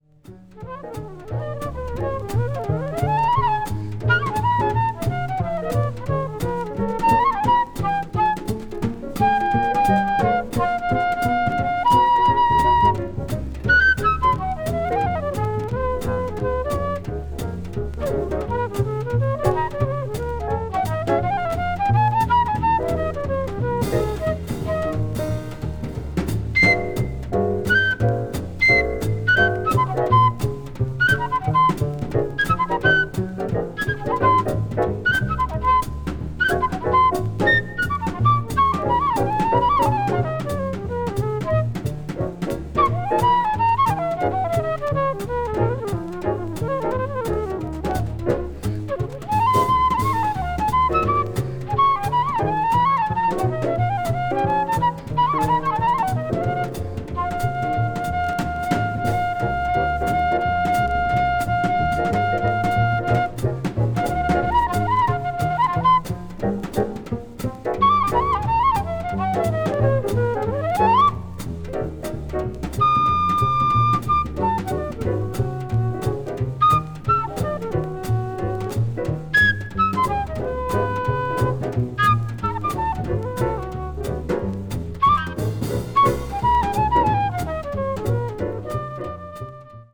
hard bop   modern jazz   post bop